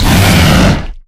vampire_hit.ogg